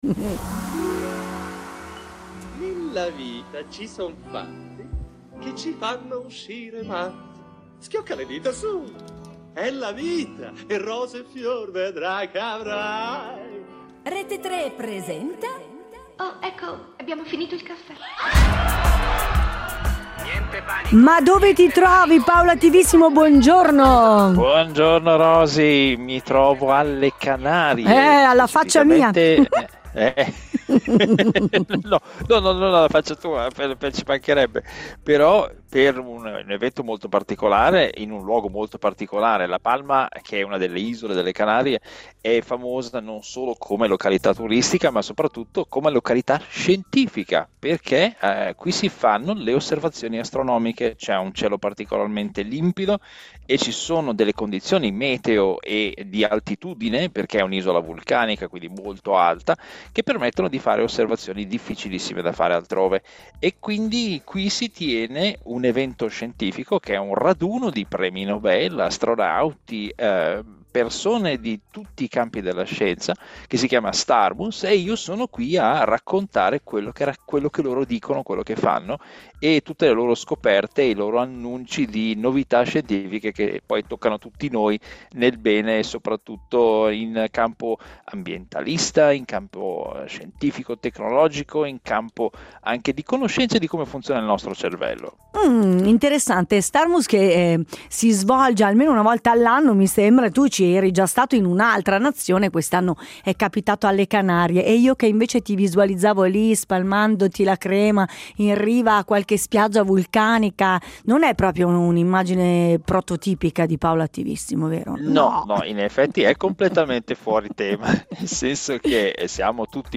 In diretta dalle Canarie